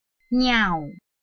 拼音查詢：【南四縣腔】ngiau ~請點選不同聲調拼音聽聽看!(例字漢字部分屬參考性質)